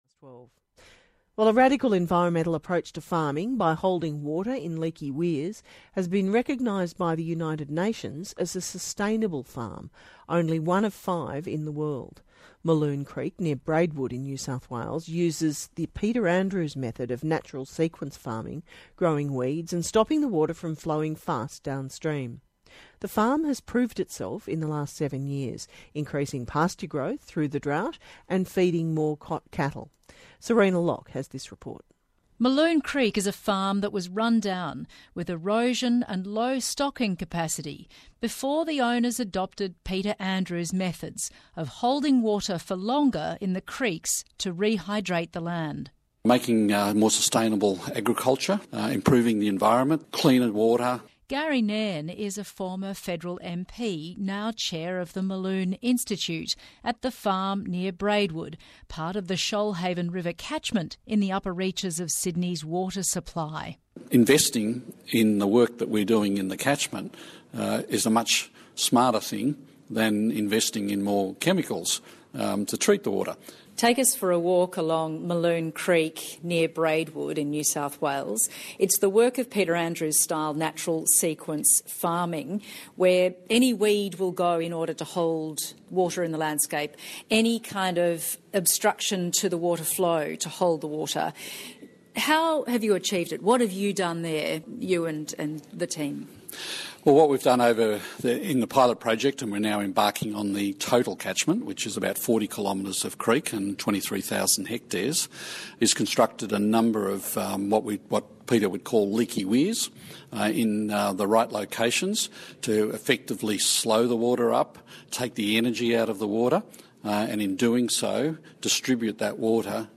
The Mulloon Institute’s Chairman Gary Nairn AO speaks with ABC Radio about his recent presentation at the UN Sustainable Development Goals’ Summit in Sydney.